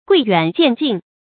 貴遠賤近 注音： ㄍㄨㄟˋ ㄧㄨㄢˇ ㄐㄧㄢˋ ㄐㄧㄣˋ 讀音讀法： 意思解釋： 以為與當世相隔久遠的就珍貴，相隔近的就低賤。